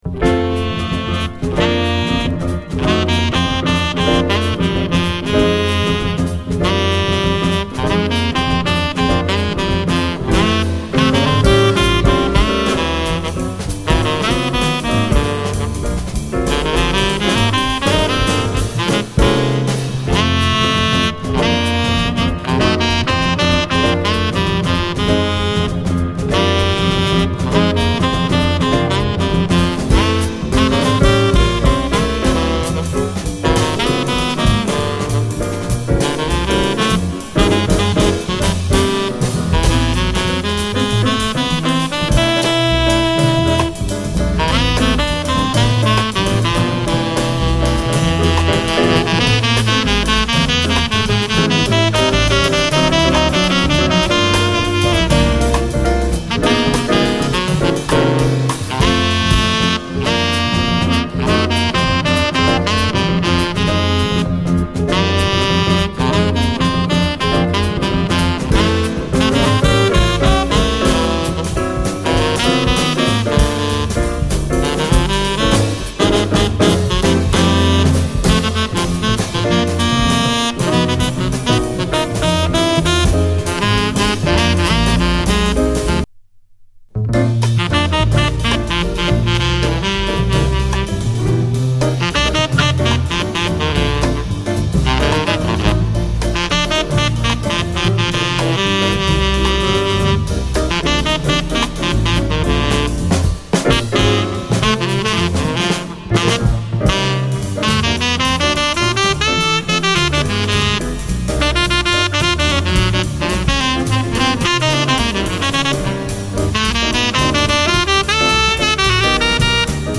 JAZZ
往年のウェスト・コースト・ジャズを思わせる、爽快で甘美なダンスフロア・ジャズ！